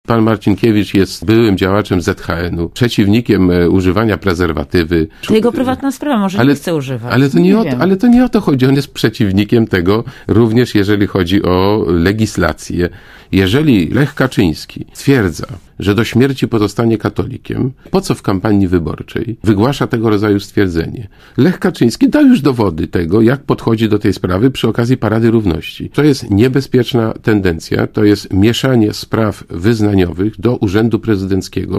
Liderzy PiS-u prywatne poglądy przekładają nad działalność publiczną – mówi Marek Borowski. W ten sposób Gość Radia ZET, komentuje wypowiedź Kazimierza Marcinkiewicza na temat homoseksualizmu.
Mówi Marek Borowski